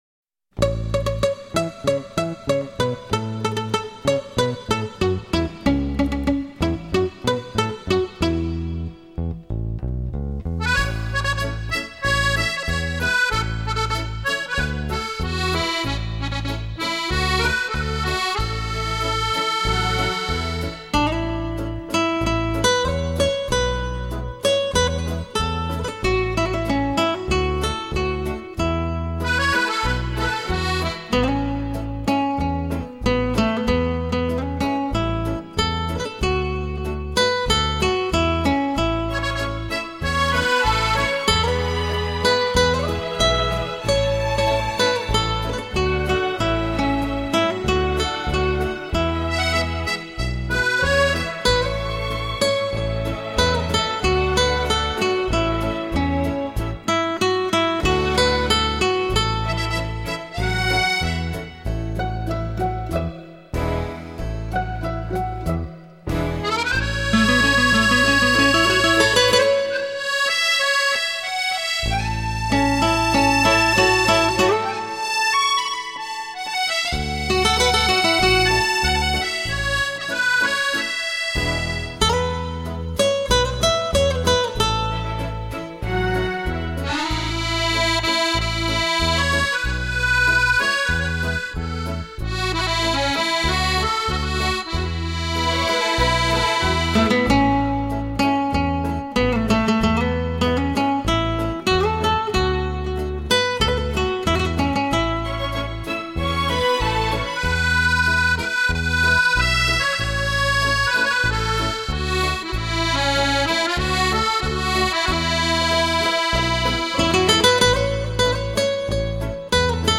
在整张精选集录音中，吉他的音色通透自然、清晰明亮而又温暖饱满，将这些宛如星空中最闪亮的星光一般的经典演绎得更加不朽！